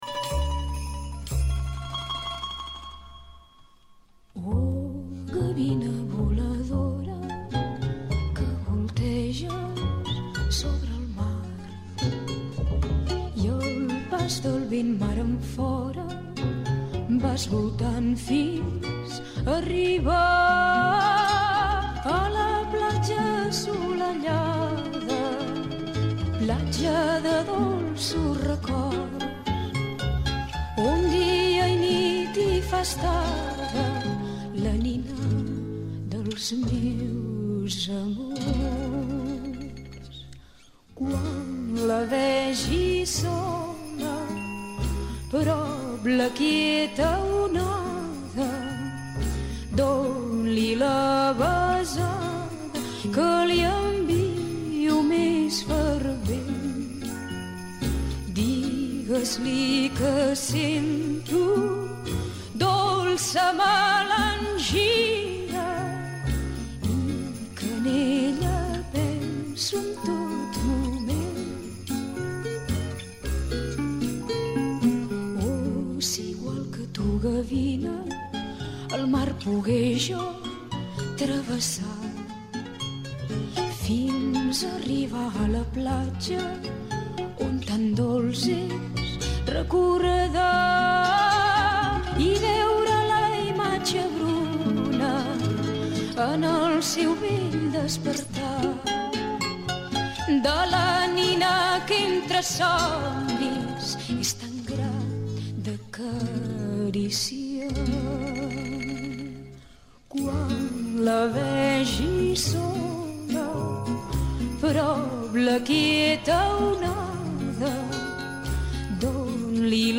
Tertúlia de majors: la vida vora la mar